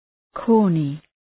{‘kɔ:rnı}